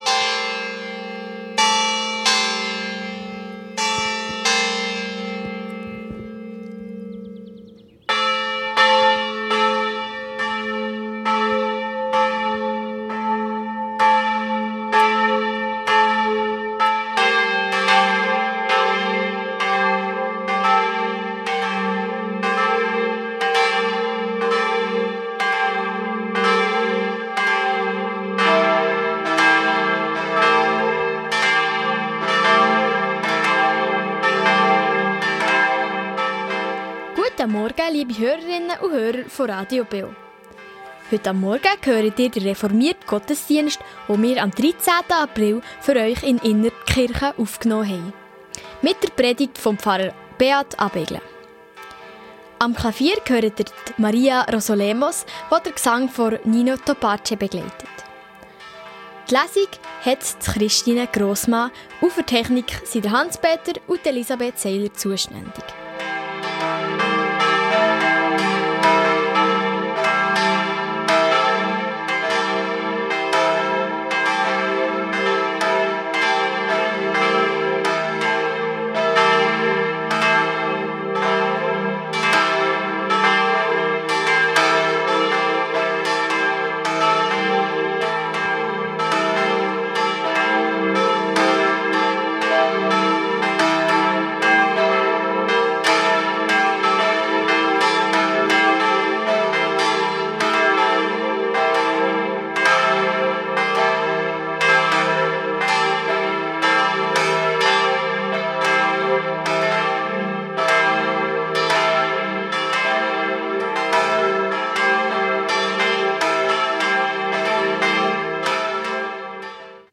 Reformierte Kirche Innertkirchen ~ Gottesdienst auf Radio BeO Podcast